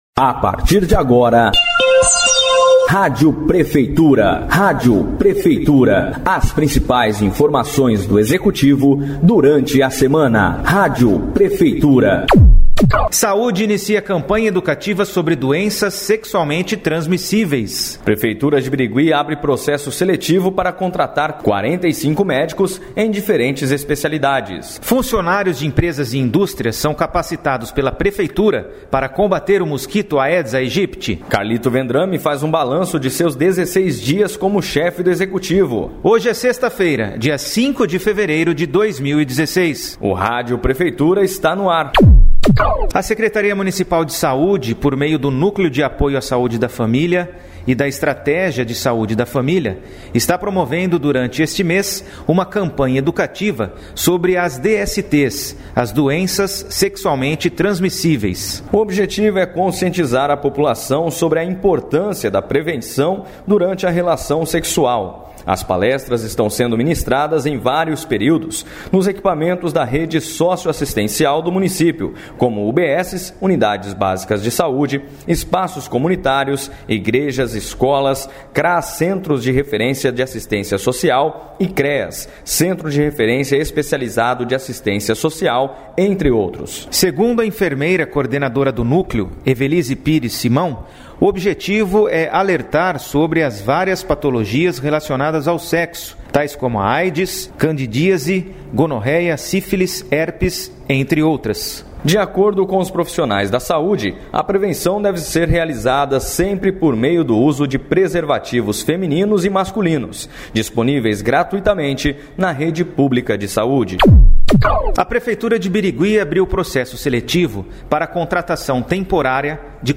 A Prefeitura de Birigui traz um dinâmico programa de rádio com as principais informações da semana do Executivo.